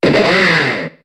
Cri d'Escroco dans Pokémon HOME.